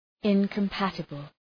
Προφορά
{,ınkəm’pætəbəl}
incompatible.mp3